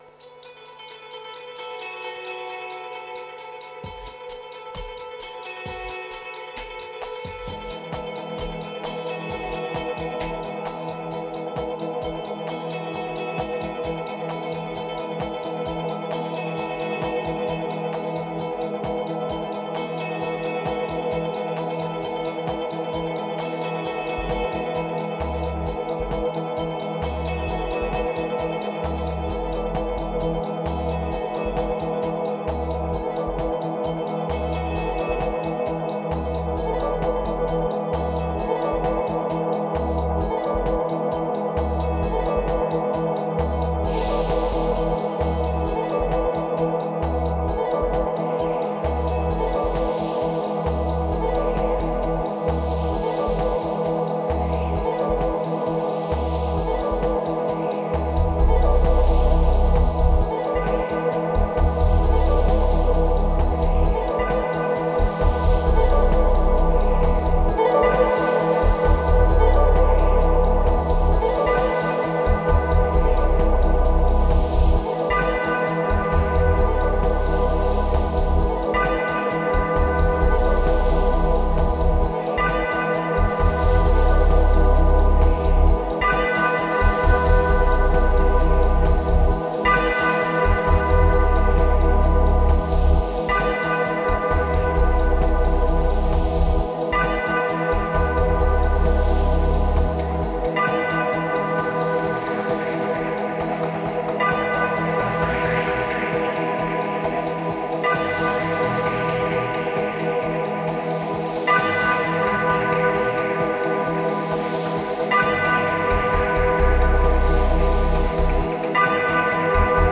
Bonne prise assez rapidement.